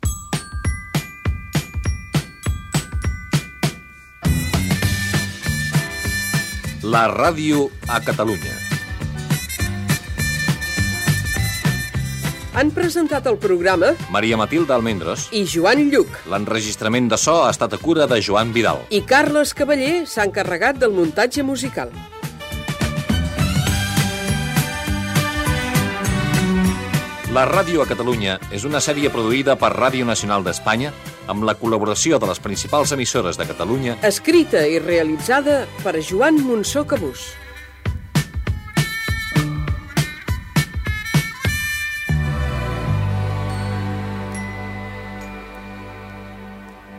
Careta final del programa, amb els noms de l'equip